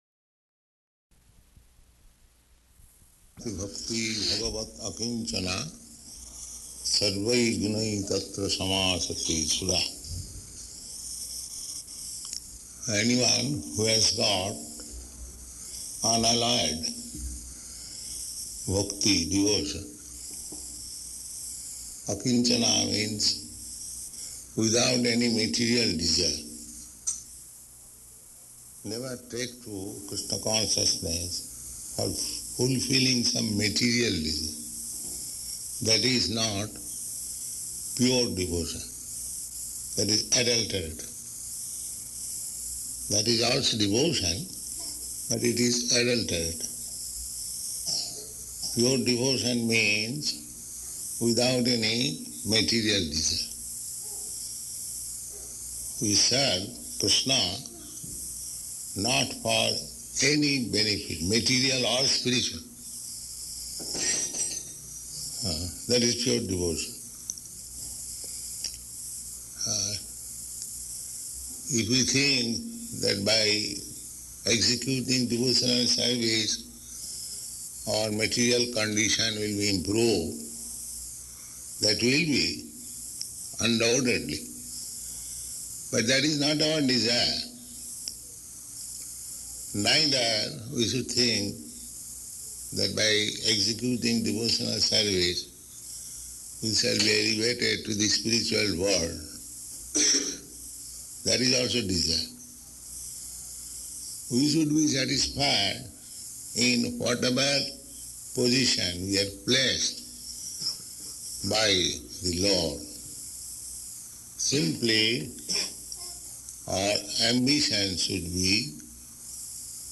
Location: Los Angeles
[Distorted]